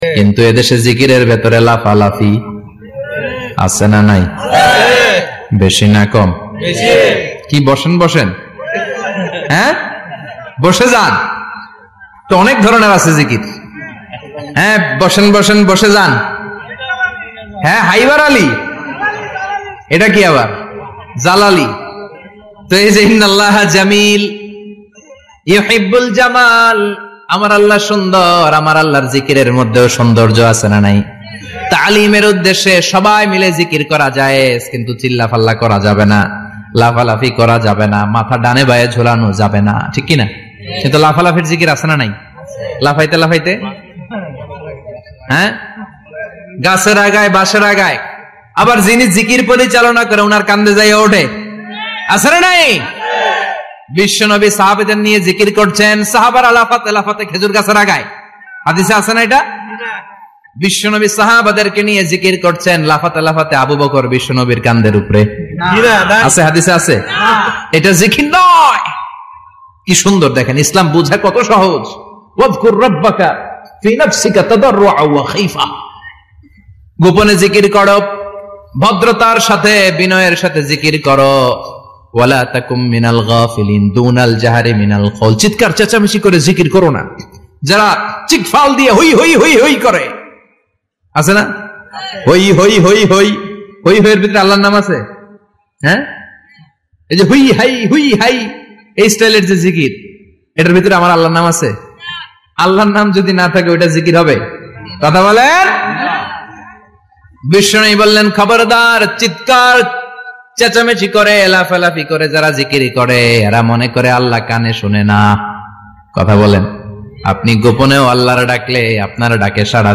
যাদের জীবনে গোনা আছে ওয়াজ আপনার জন্য পাপ মুছে ফেলার ওয়াজ